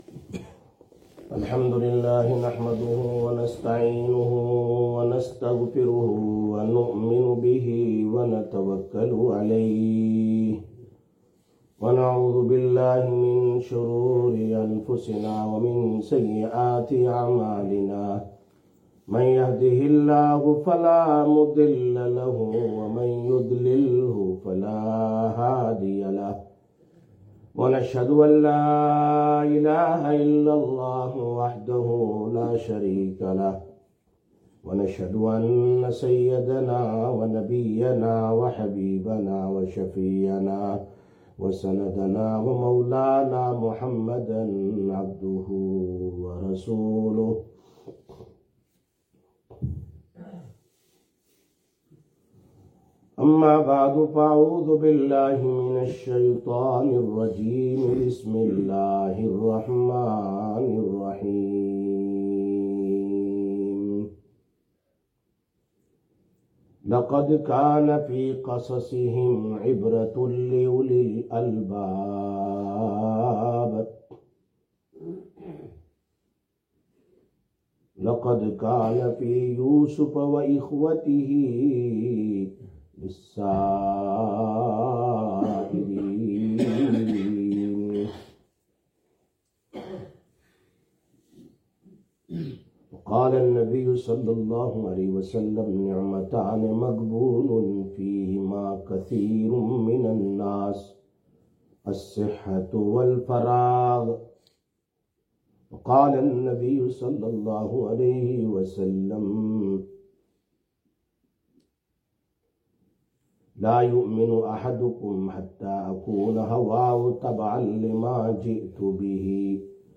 27/12/2024 Jumma Bayan, Masjid Quba